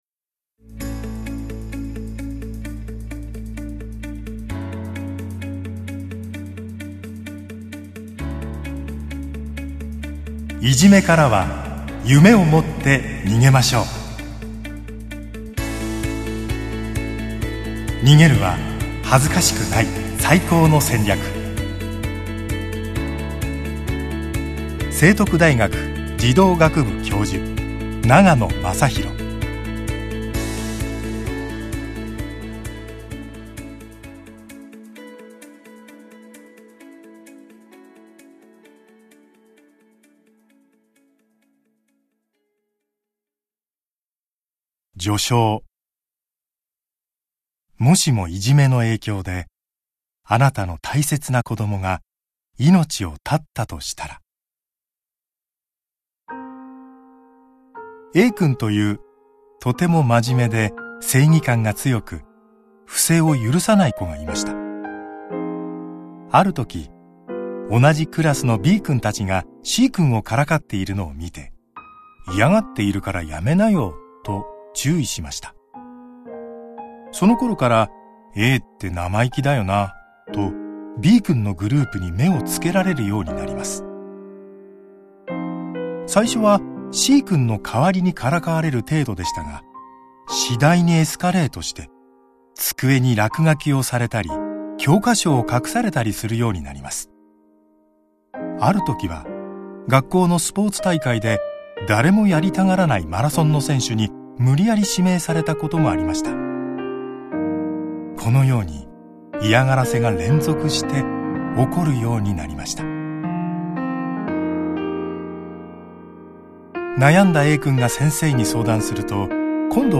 [オーディオブック] いじめからは夢を持って逃げましょう！「逃げる」は、恥ずかしくない「最高の戦略」